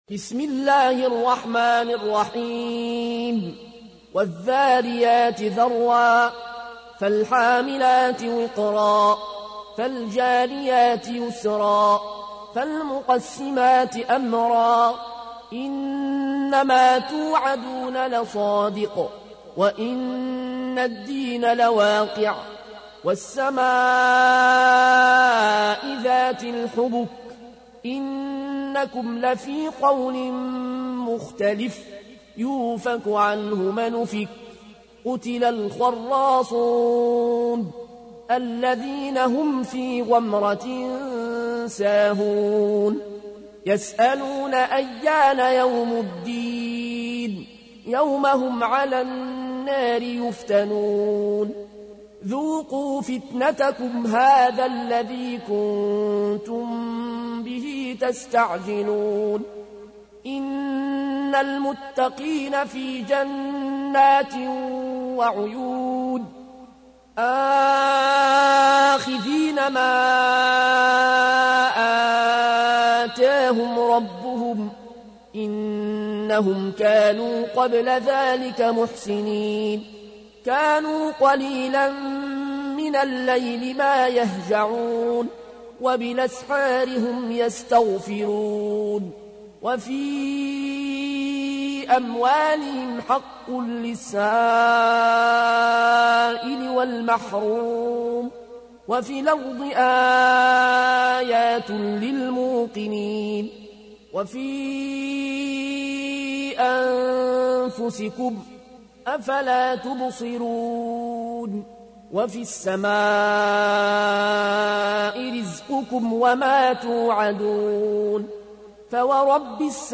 تحميل : 51. سورة الذاريات / القارئ العيون الكوشي / القرآن الكريم / موقع يا حسين